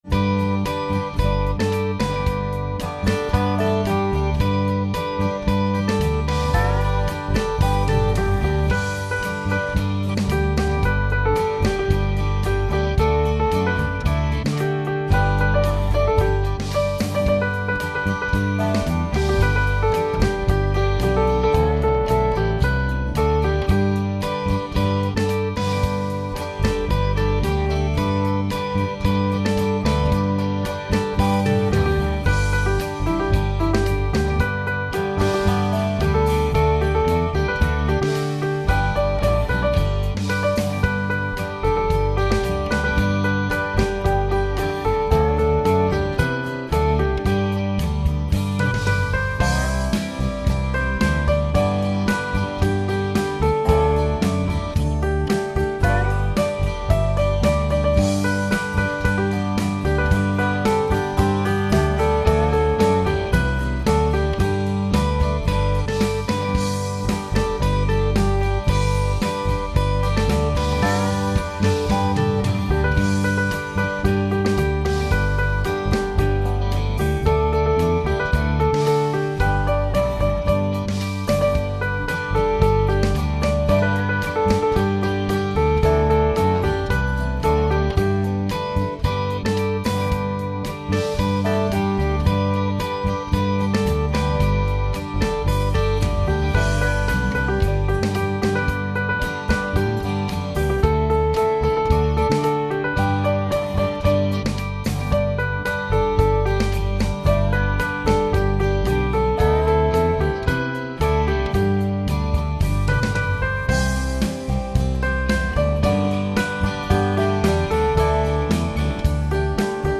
It goes fairly high and realistically is a solo piece.